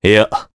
voices
Crow-Vox-Deny_jp.wav